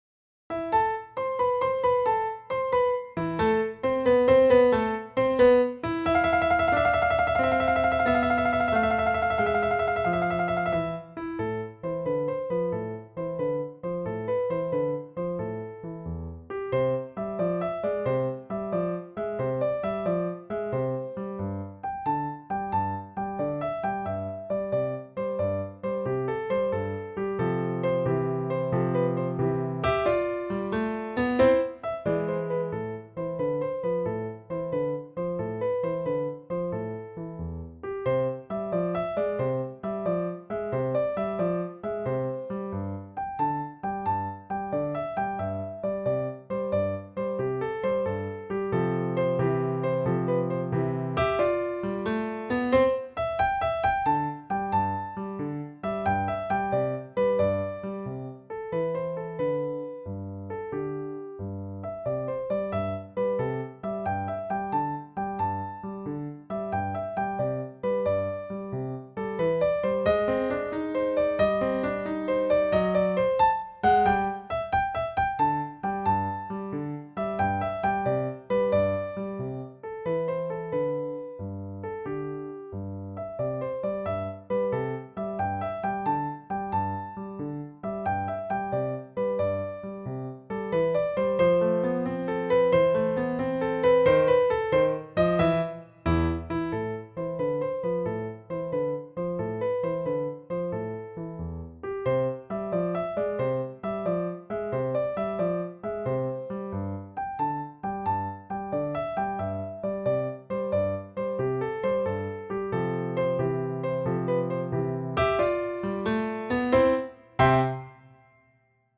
instrumental piece
instrumental versions